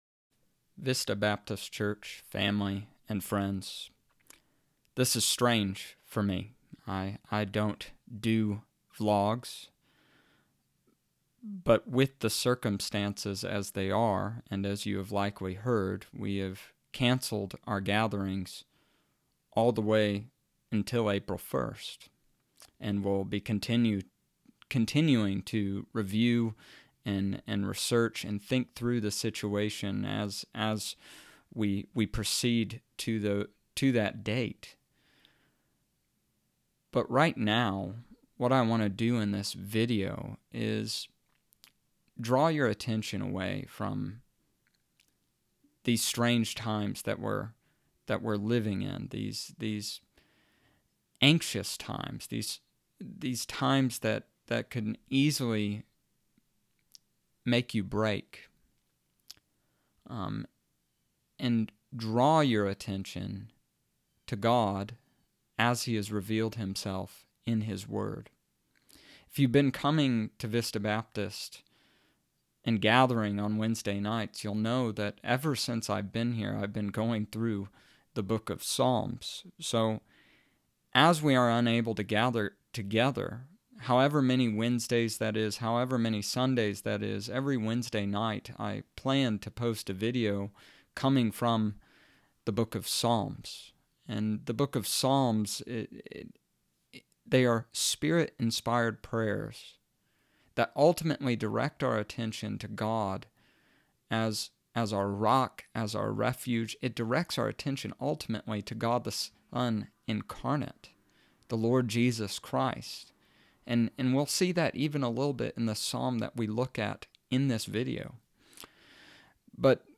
Wednesday Devotion: Psalm 95
Wednesday night and Sunday night will be more like Bible Study/Devotionals.